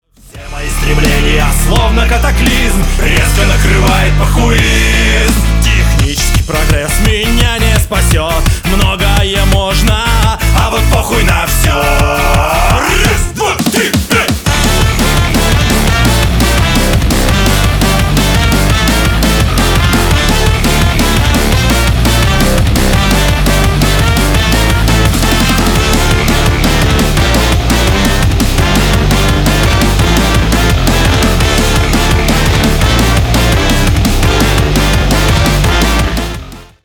мужской голос
веселые
нецензурная лексика